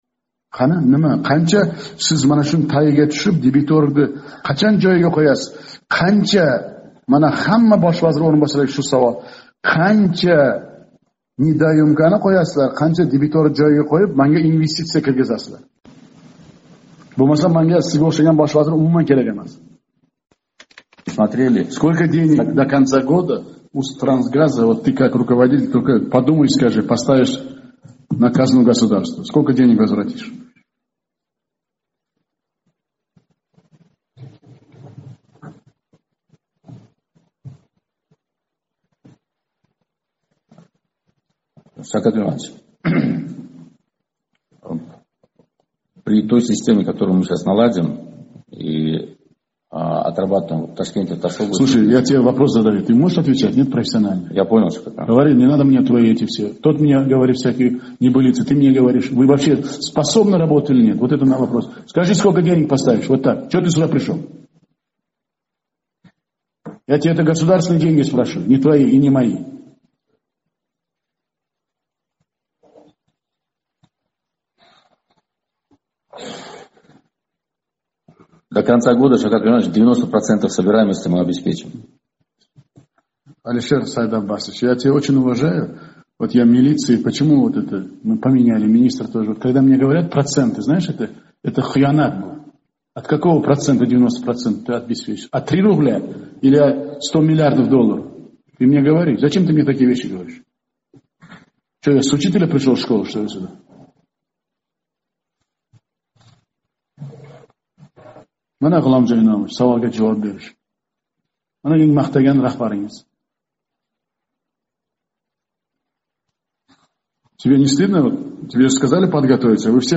Xususan¸ joriy yil boshida o‘tkazilgan hukumat yig‘ilishlaridan birida prezident¸ gaz va neft sohasidagi ahvol haqida gapira turib¸ vazirlik darajasidagi “O‘zbekneftgaz” boshqaruvi raisi Alisher Sultonov bilan rus tilida gaplashishga o‘tgan edi.